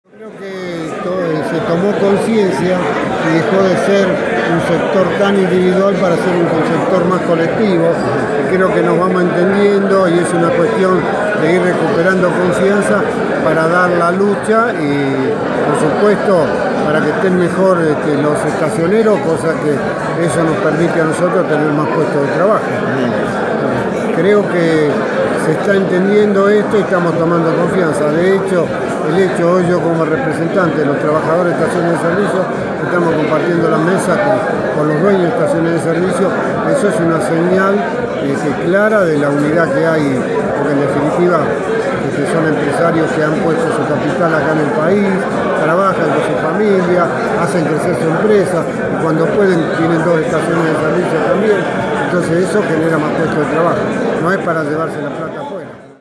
en el marco de la celebración de fin de año de la Federación de Entidades de Combustibles